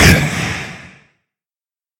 assets / minecraft / sounds / mob / wither / hurt4.ogg
hurt4.ogg